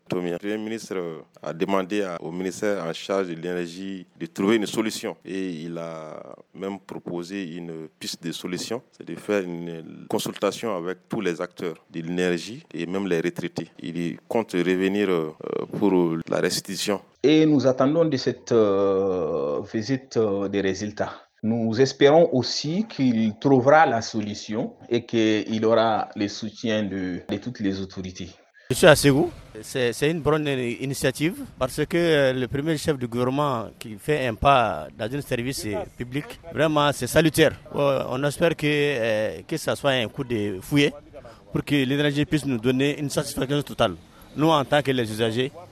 VOX-POP-DEMARCHE-PM.mp3